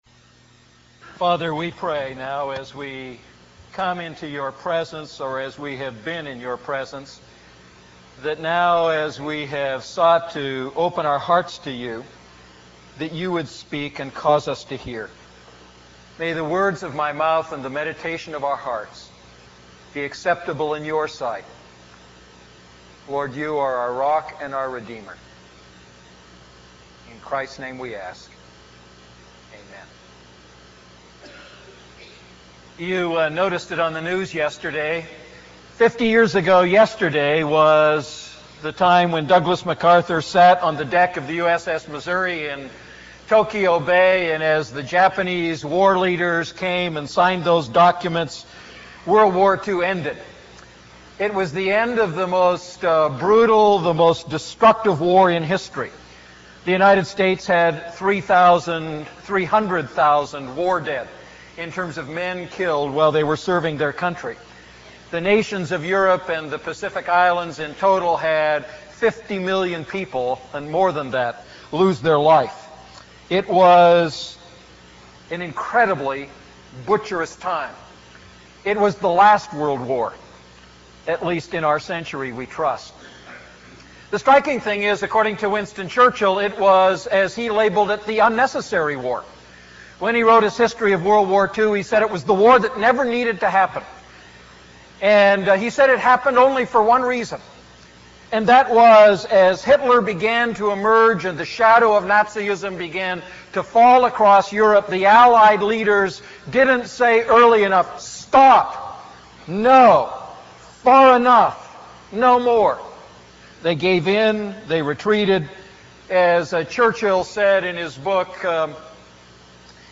A message from the series "Wise Walking."